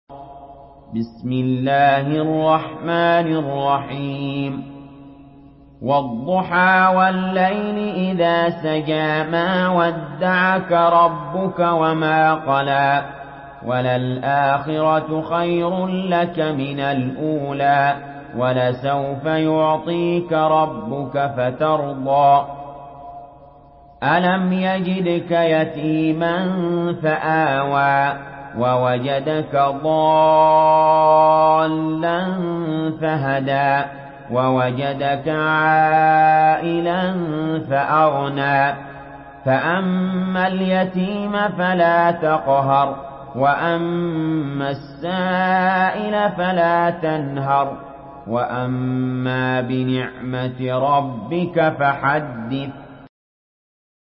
Surah Ad-Duhaa MP3 by Ali Jaber in Hafs An Asim narration.
Murattal Hafs An Asim